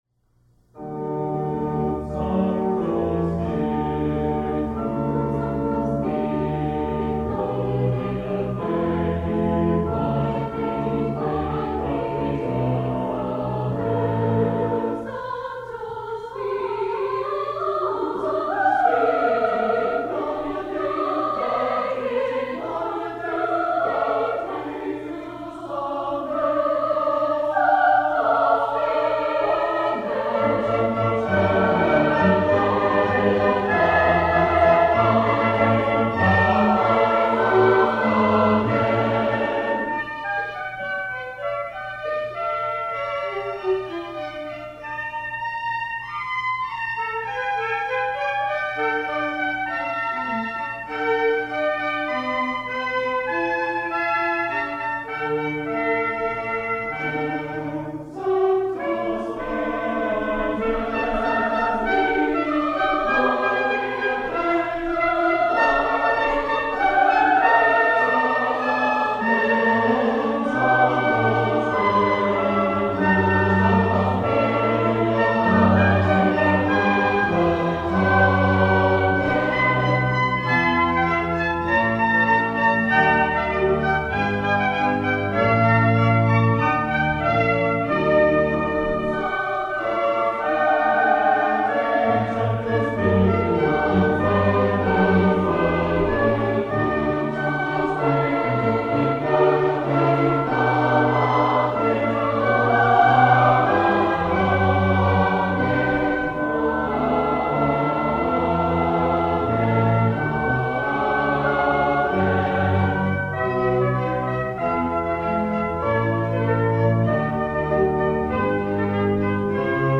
Southern Nazarene University Choral Society in Christmas Concert. Recorded Monday, 07 Dec. 1987 in Cantrell Music Hall.
Organ